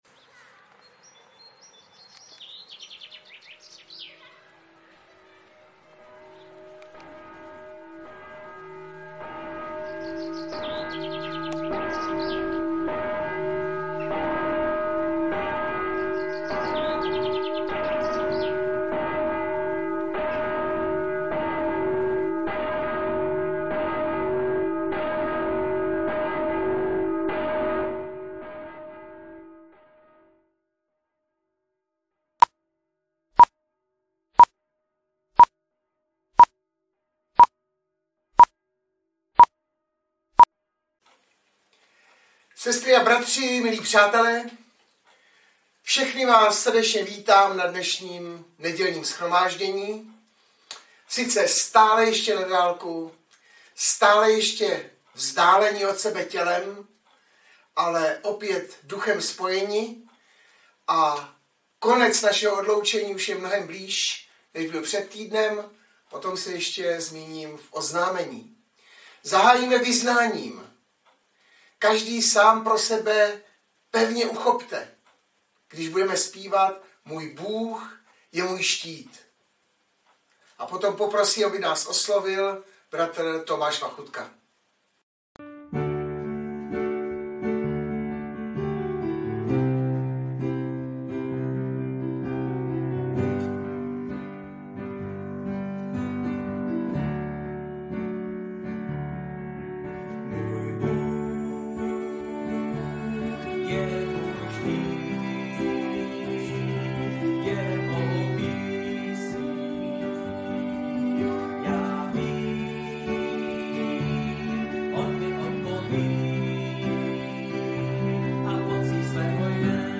Baptisté v Litoměřicích
Audiozáznam kázání si můžete také uložit do PC na tomto odkazu.